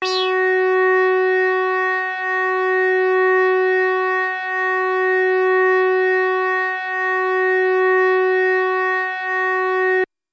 标签： midivelocity7 F4 midinote66 FenderChromaPolaris synthesizer singlenote multisample
声道立体声